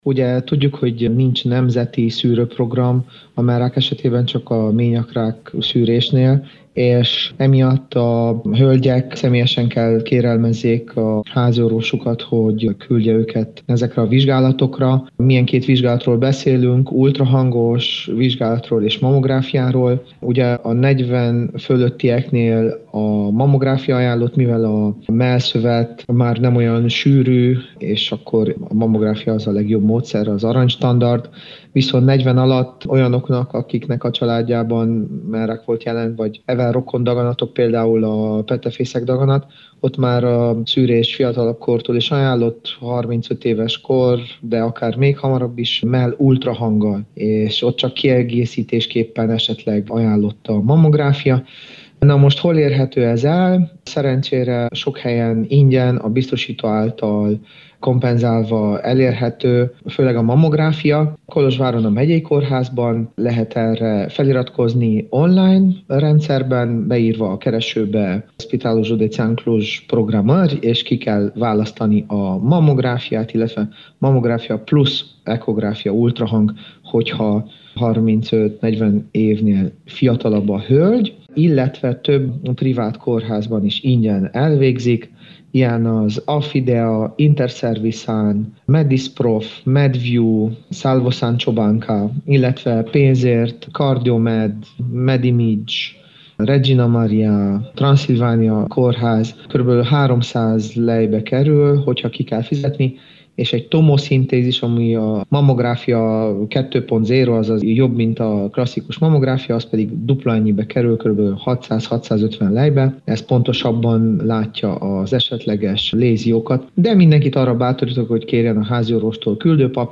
Riporter: